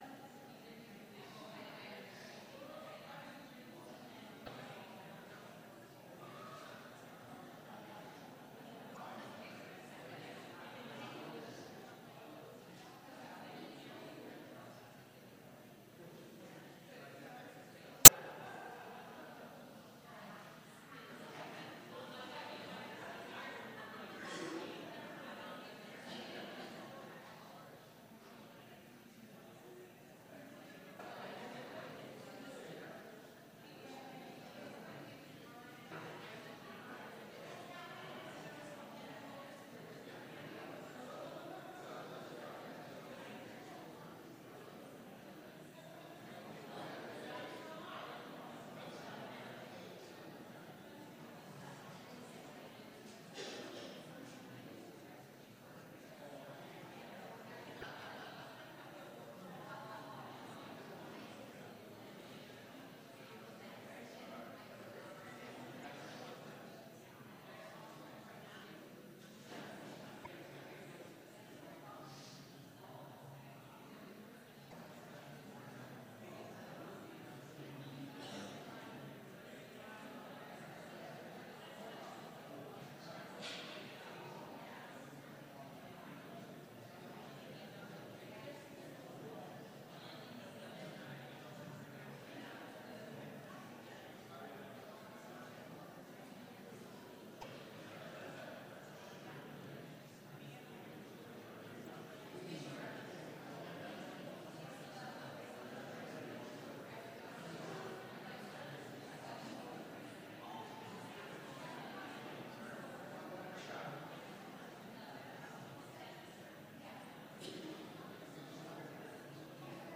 Complete service audio for Special - Lutheran Teachers' Conference